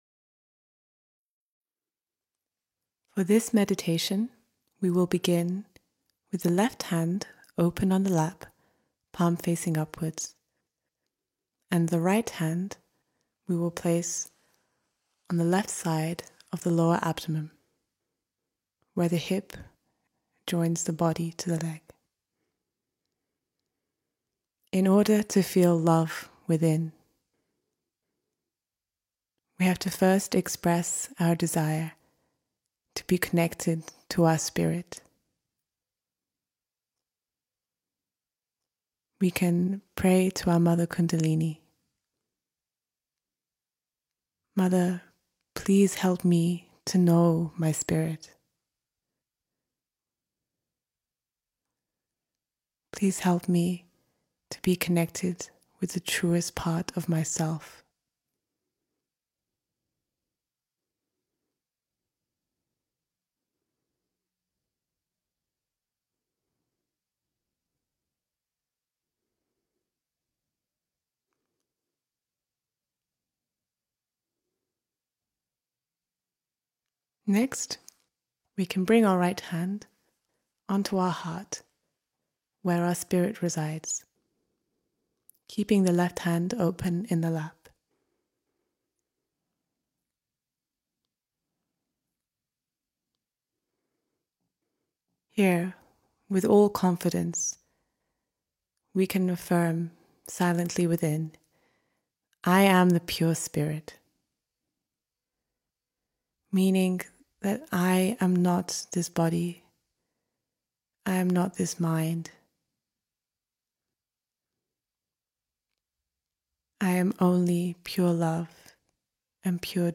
MEDITATION Shri Mataji Nirmala Devi founder 0:00 Delving Deeper All pervading power